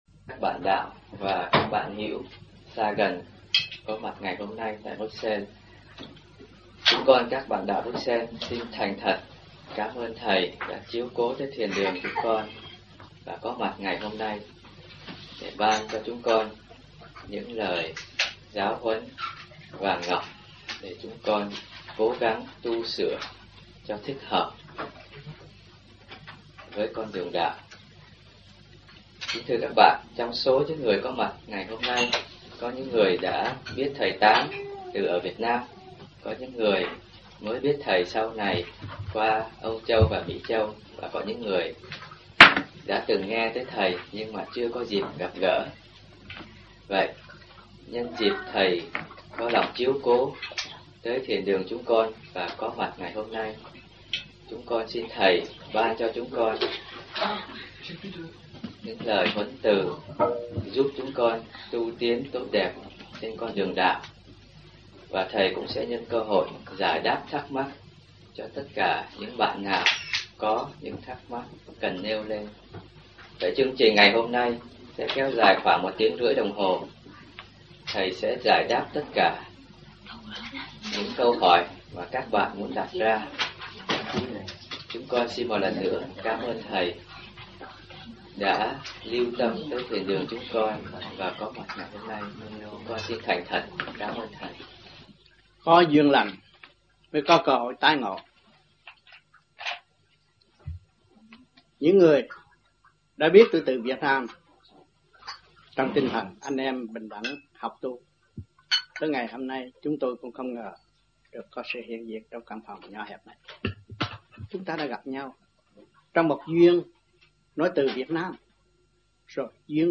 1989-09-23 - BRUXELLES - THUYẾT PHÁP VÀ VẤN ĐẠO